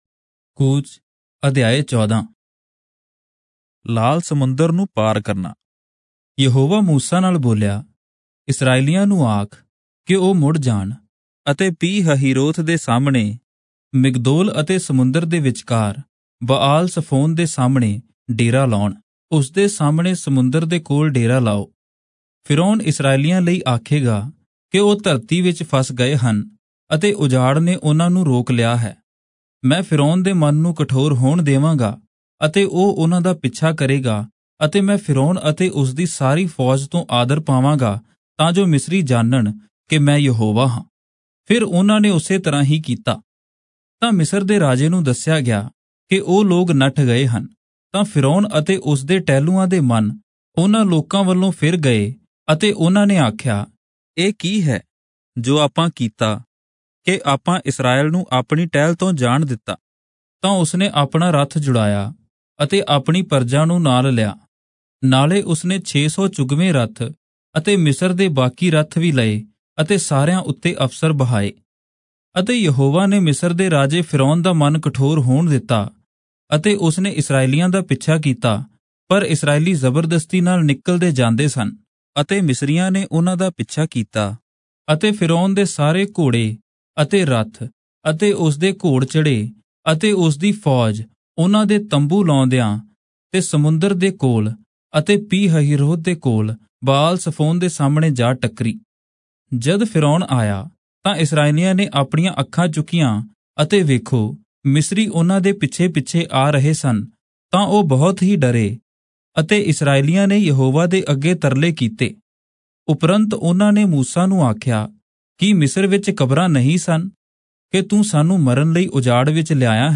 Punjabi Audio Bible - Exodus 32 in Irvpa bible version